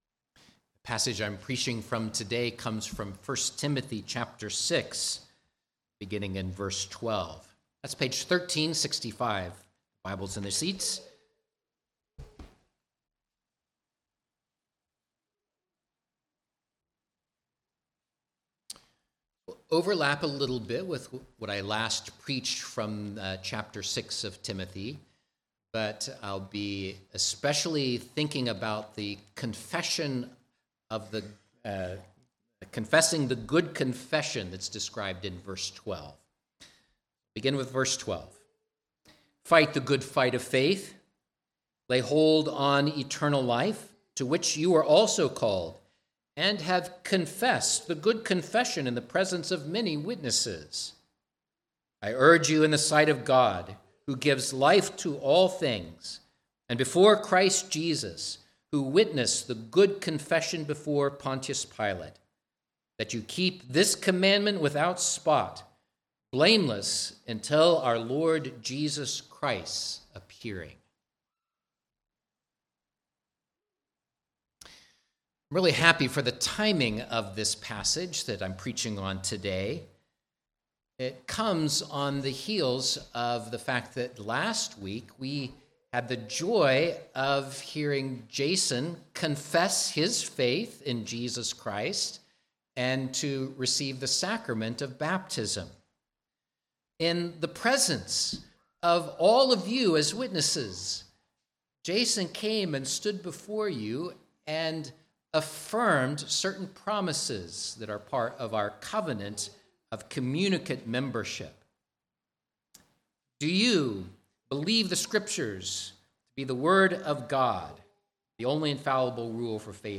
Glorifying Jesus Christ through biblical preaching and teaching.